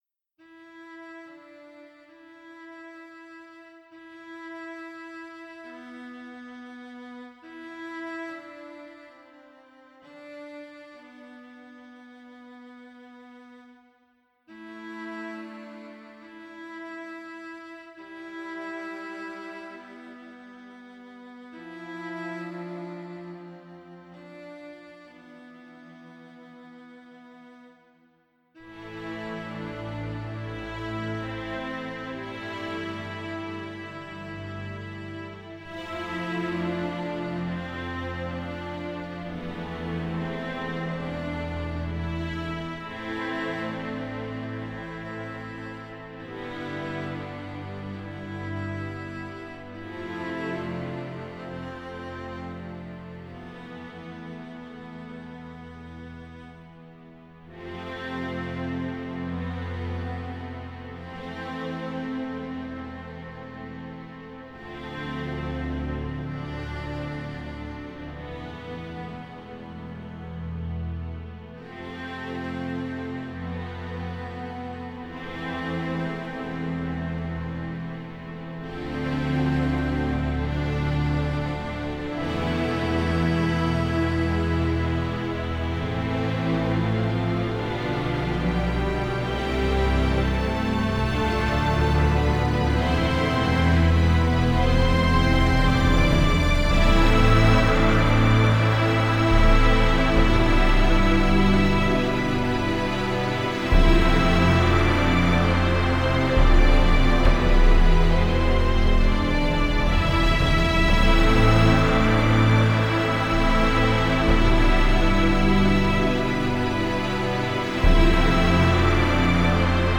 2003   Genre: Soundtrack   Artist